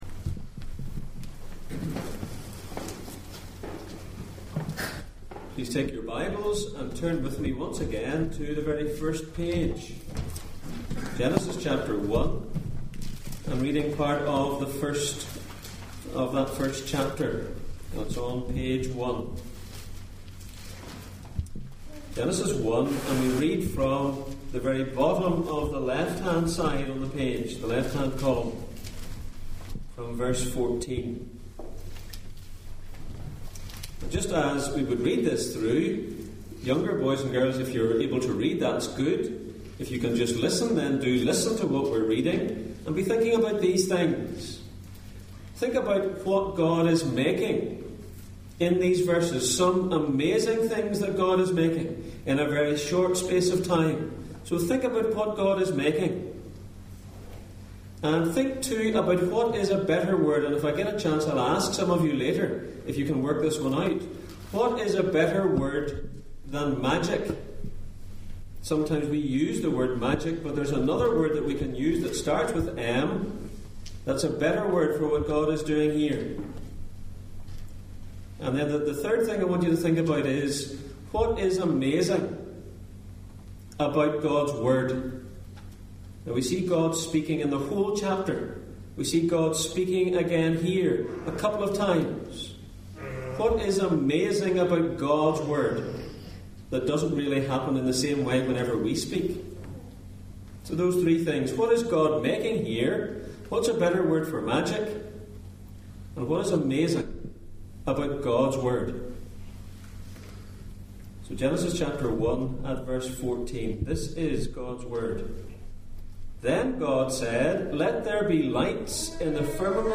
Back to the beginning Passage: Genesis 1:14-23, Jeremiah 10:2-3, Job 41:1-34 Service Type: Sunday Morning